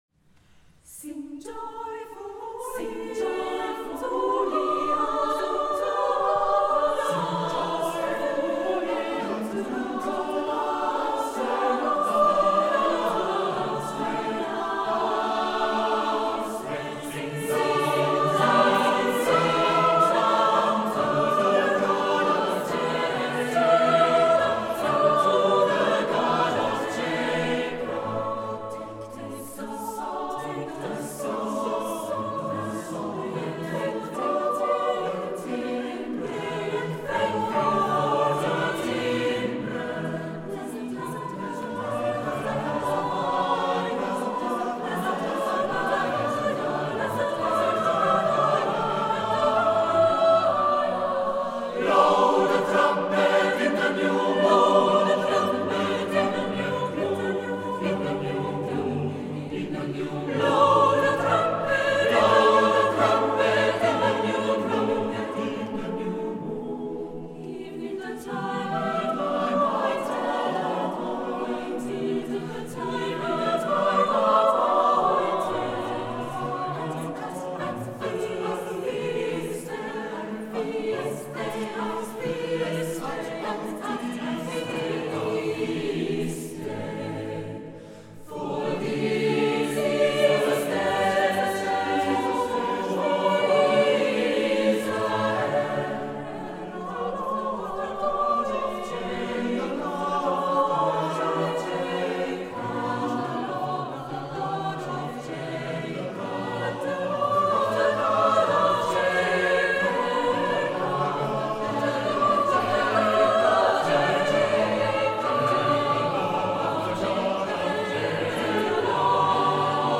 Tëuta su ai 27.04.2025 tla dlieja de Urtijëi